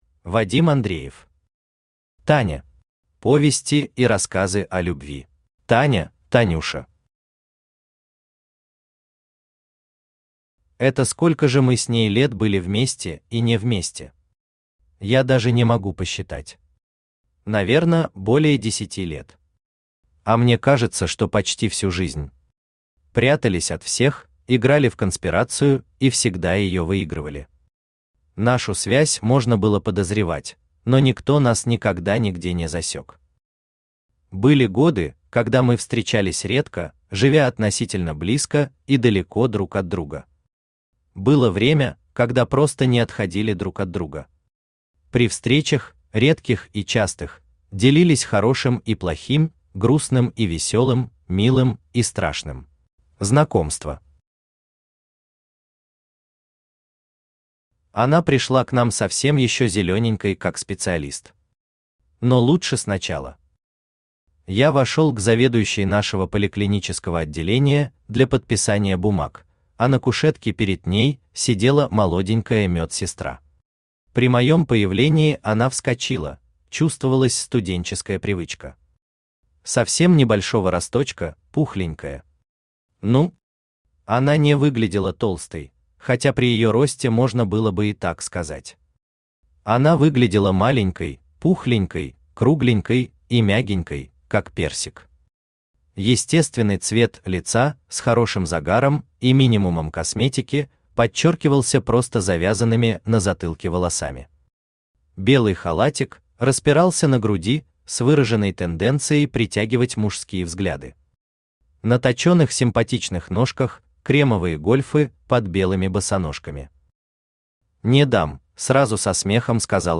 Аудиокнига Таня. Повести и рассказы о любви | Библиотека аудиокниг
Повести и рассказы о любви Автор Вадим Андреев Читает аудиокнигу Авточтец ЛитРес.